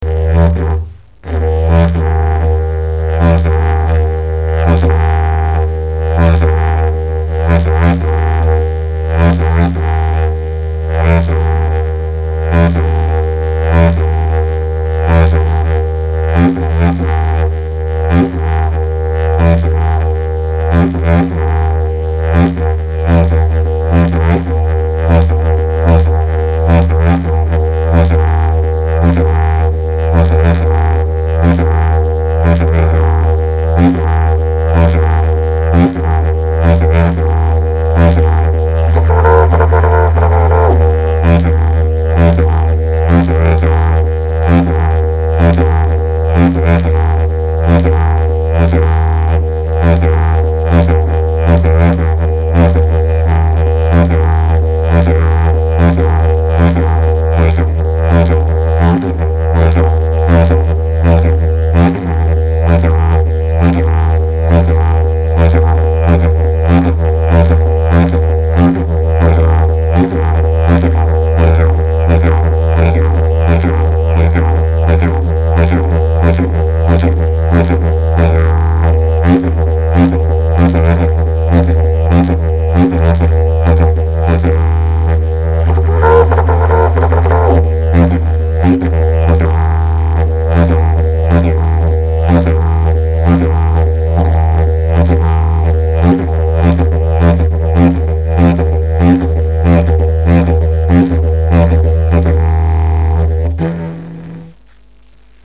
Je trouve ca à la fois dynamique et reposant et les hoot très doux passent pas mal du tout.